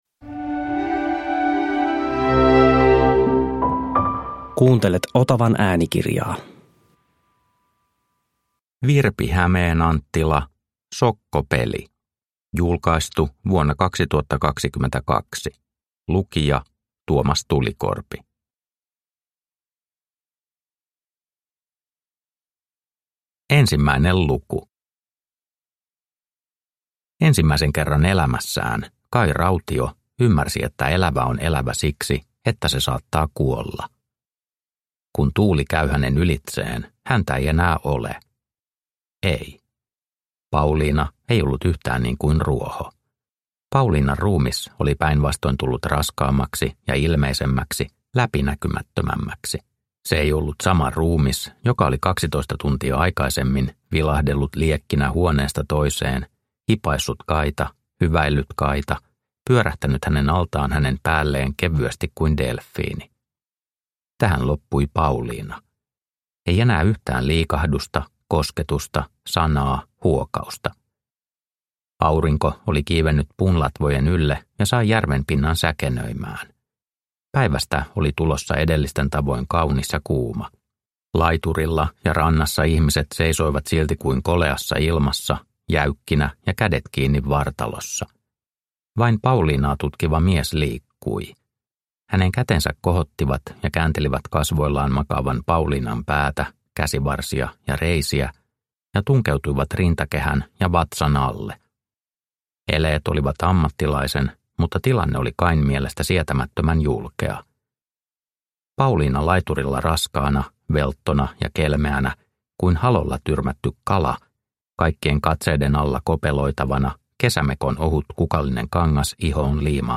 Sokkopeli – Ljudbok – Laddas ner